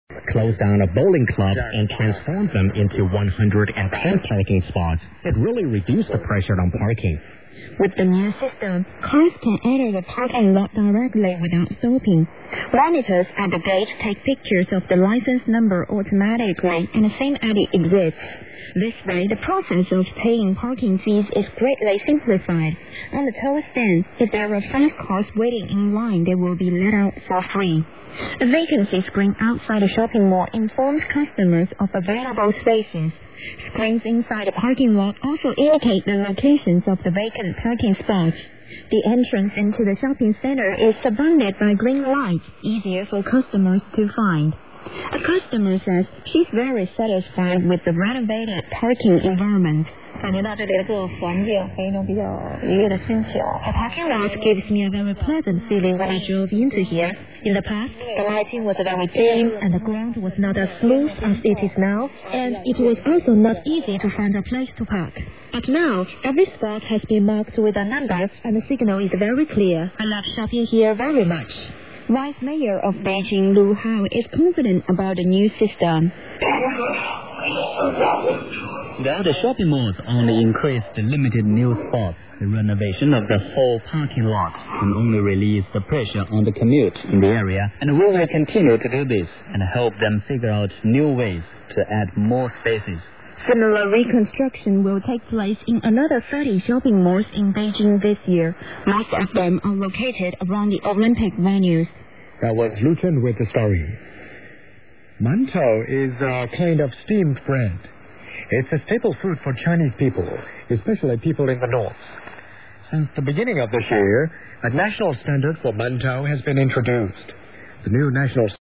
DXPedition Itatiaiuçu-MG JAN-2008
ICOM IC-R75 c/DSP + Ham Radio Deluxe
02 Antenas Super KAZ 90 graus uma da outra NORTE-SUL E LESTE-OESTE